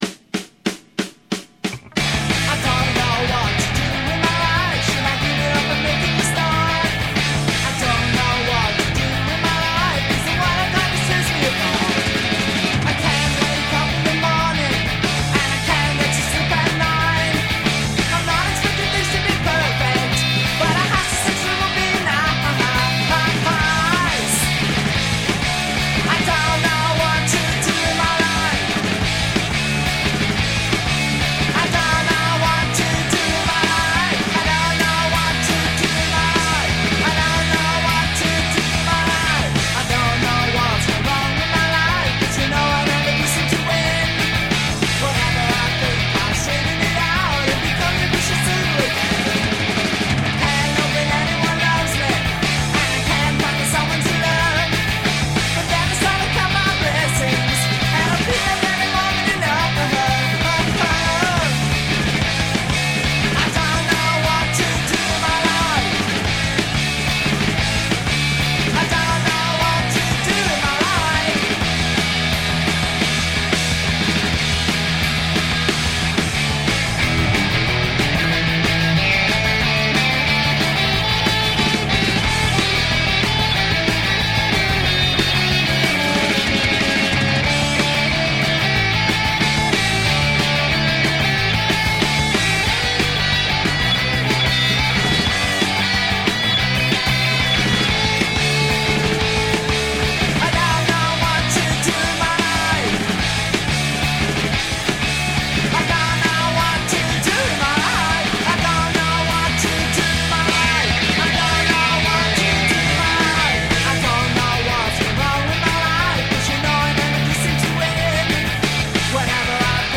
Fused Pop craftsmanship with rapid fire Punk energy.